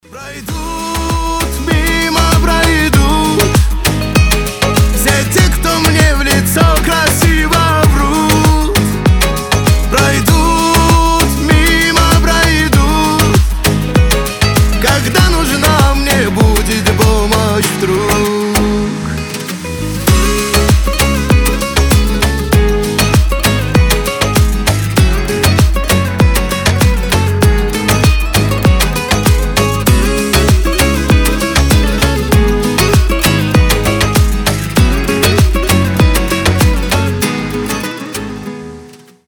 • Качество: 320, Stereo
гитара